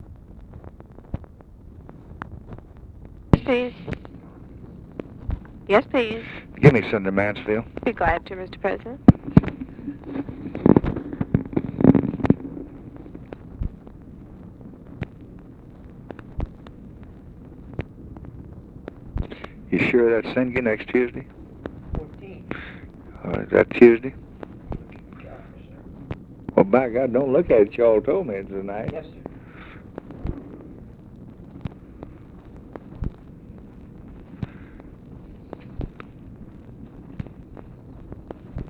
Conversation with TELEPHONE OPERATOR and OFFICE CONVERSATION
Secret White House Tapes